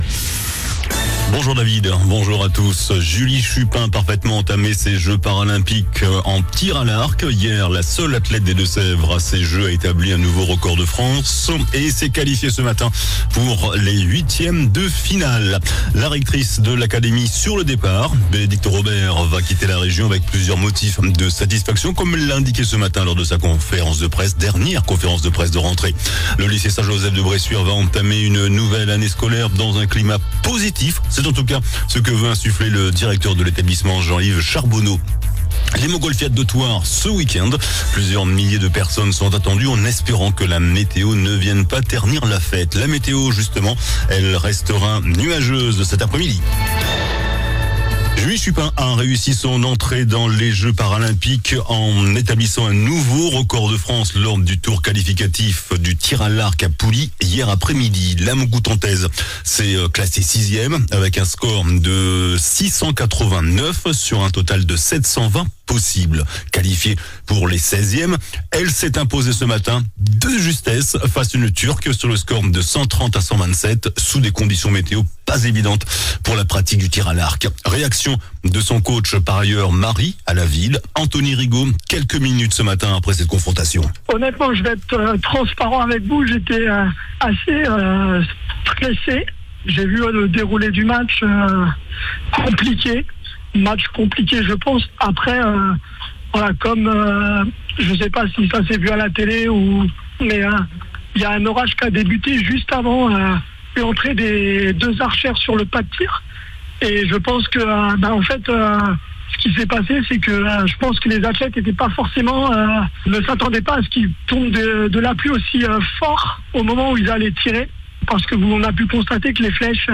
JOURNAL DU VENDREDI 30 AOÛT ( MIDI )